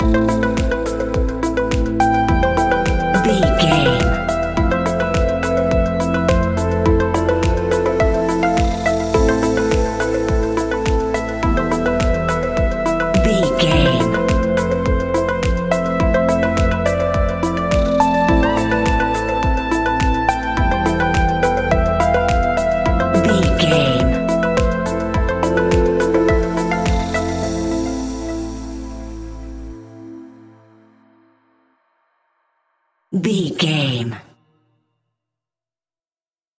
Aeolian/Minor
groovy
calm
smooth
dreamy
uplifting
piano
drum machine
synthesiser
house
electro house
instrumentals
synth leads
synth bass